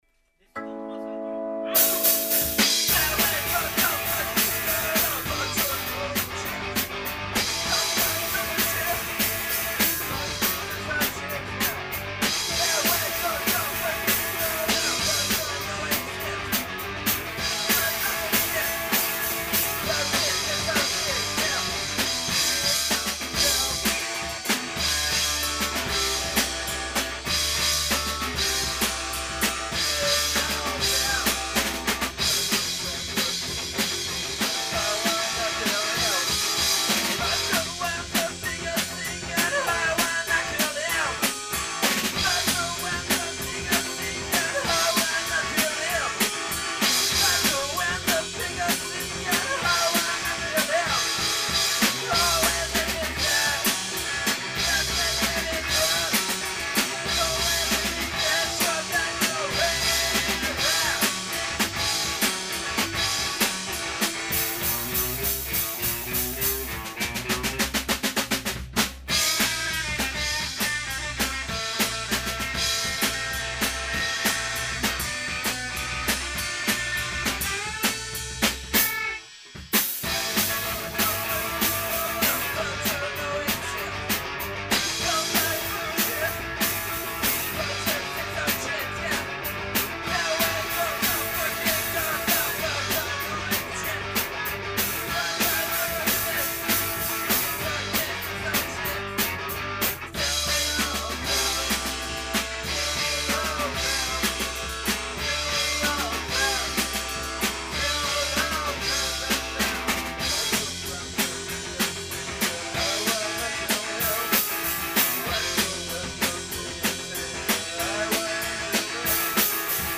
Pour ma part, mon petit groupe de garage rock The Sonic Spank met en place des nouveautés (aperçu rapide enregistré en répèt) :